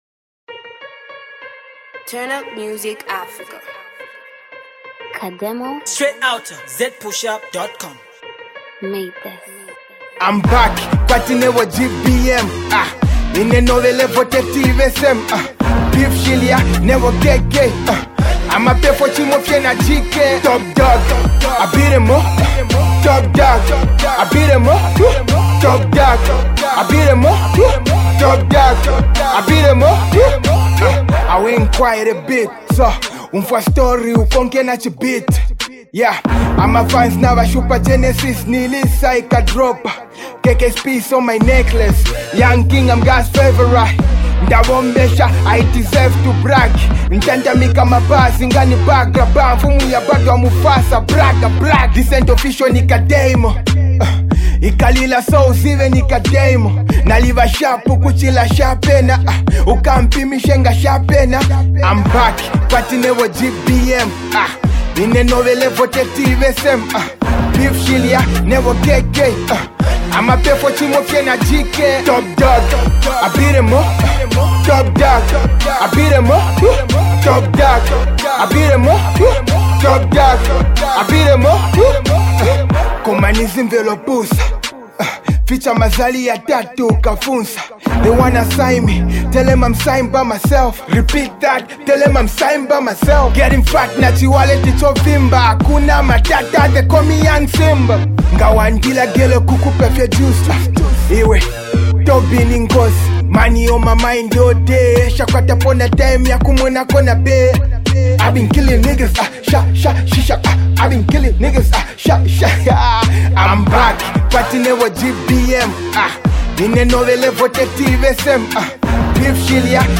Dope HipHop banger